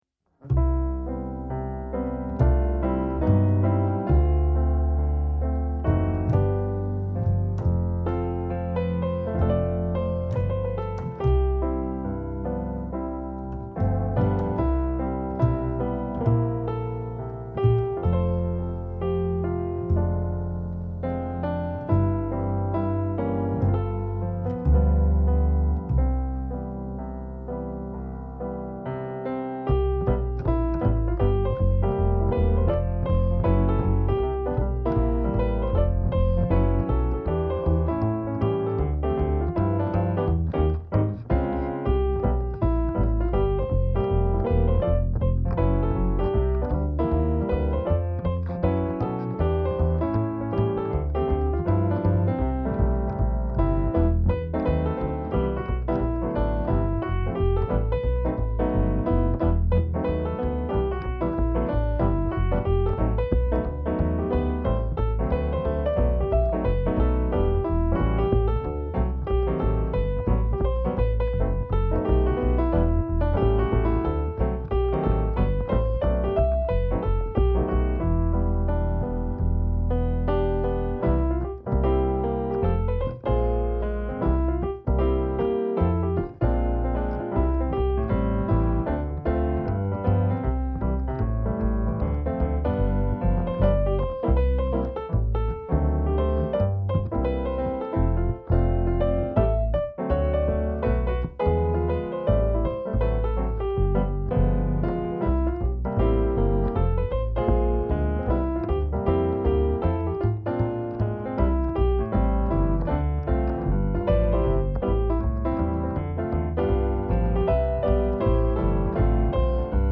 (Piano, Double Bass)